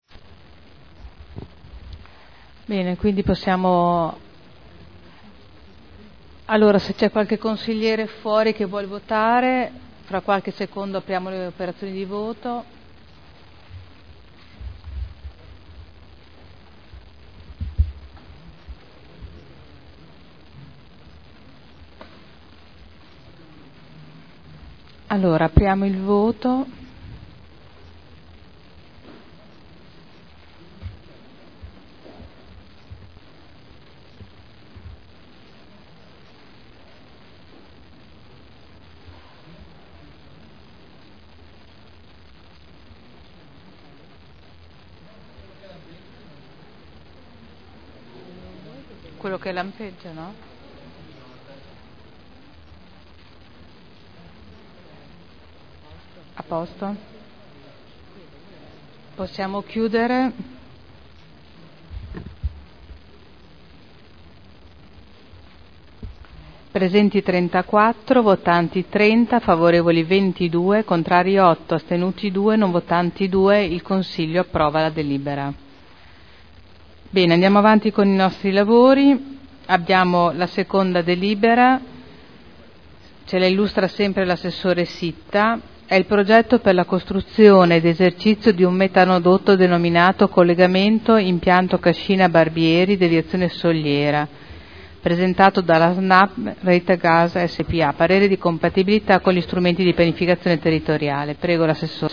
Seduta del 14 novembre Zona elementare n. 50 Area 13 di proprietà comunale - Parere favorevole all'attuazione delle previsioni urbanistiche dell'area con permesso di costruire convenzionato in deroga al Piano particolareggiato (Art. 31.23 RUE) Votazione